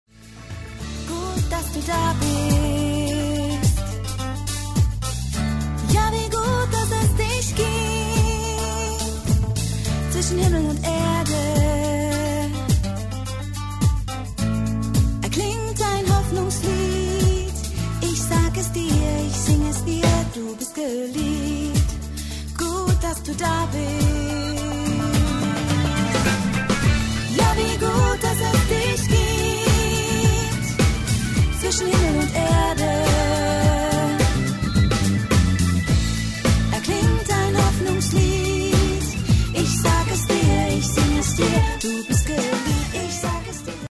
• moderne christliche Rock- und Pop-Songs
• einfühlsam und abwechslungsreich arrangiert